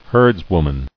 [herds·wom·an]